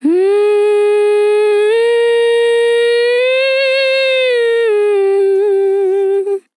TEN VOCAL FILL 22 Sample
Categories: Vocals Tags: dry, english, female, fill, sample, TEN VOCAL FILL, Tension